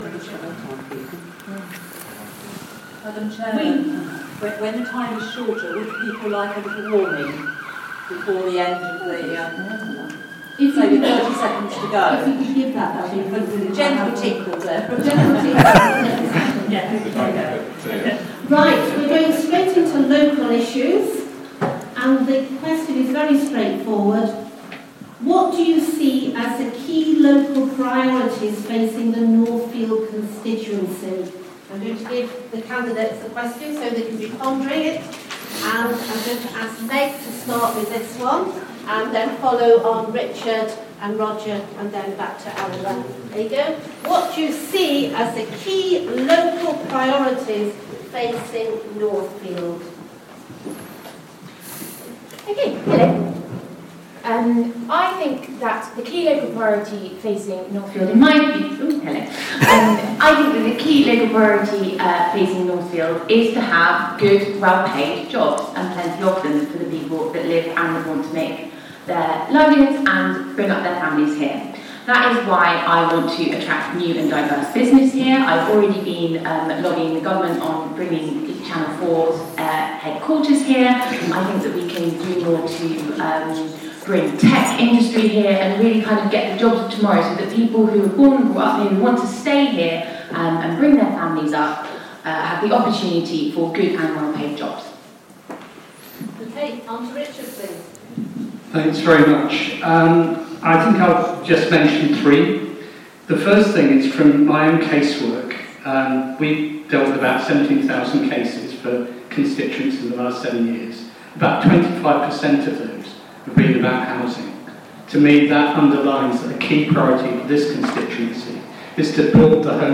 The Northfield hustings of 26 May 2017. Q: What do the candidates see as the key local priorities facing Northfield?